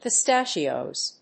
/pʌˈstæʃiˌoz(米国英語), pʌˈstæʃi:ˌəʊz(英国英語)/